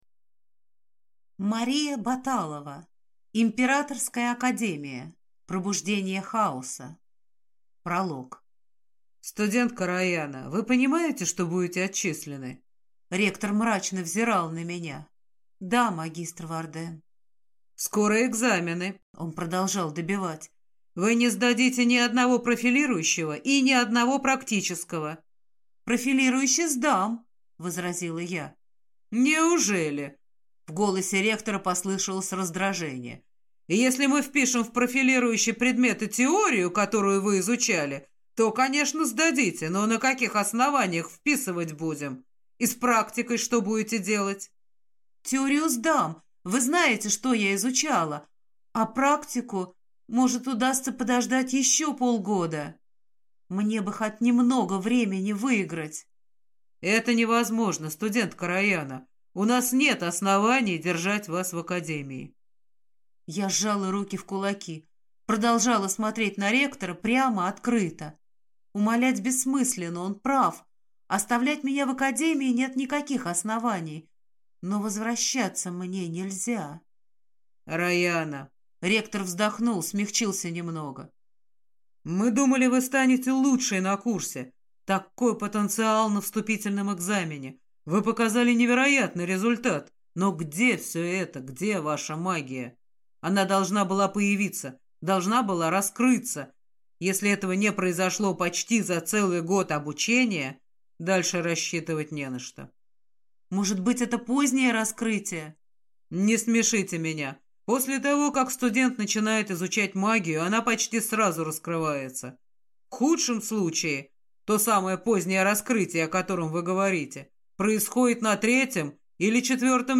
Аудиокнига Императорская академия. Пробуждение хаоса | Библиотека аудиокниг